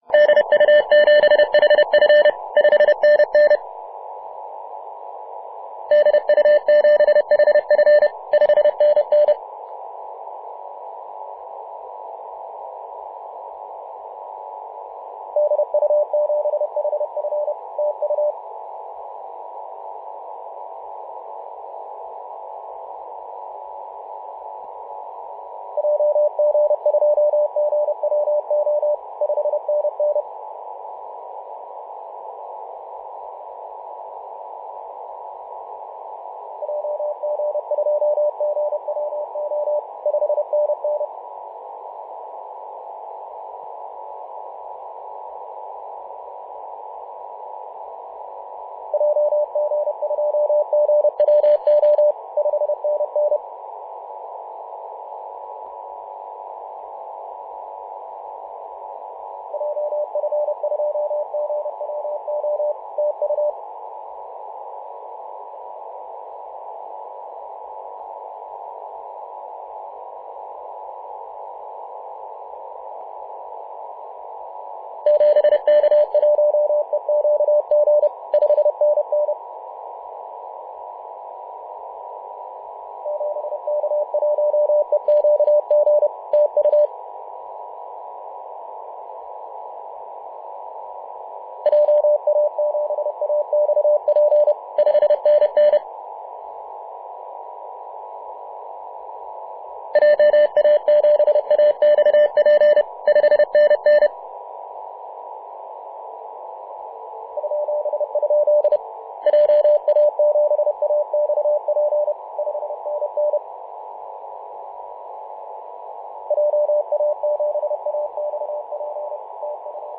28.011MHz CW